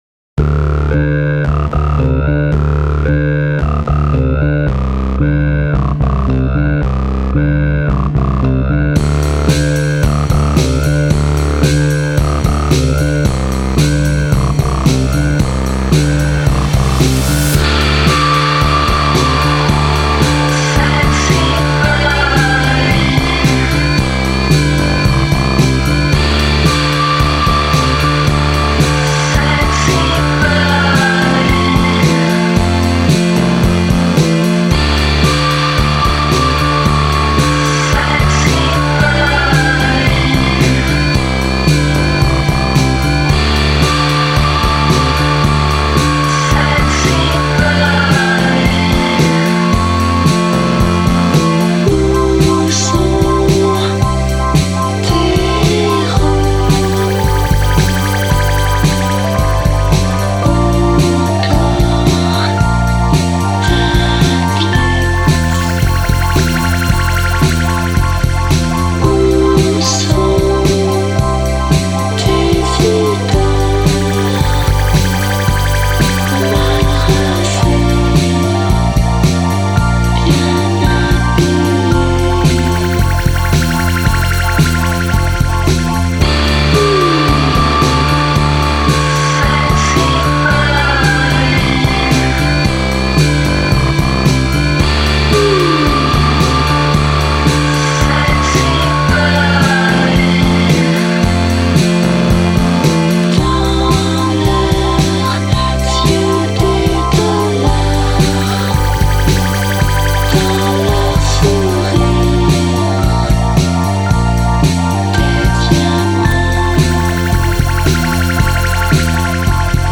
Filed under disco, electronica